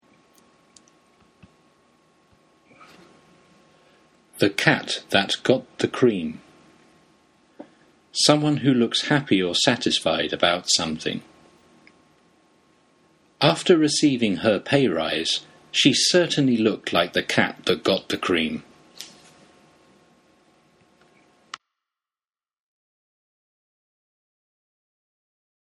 英語ネイティブによる発音は下記のリンクをクリックしてくださ い。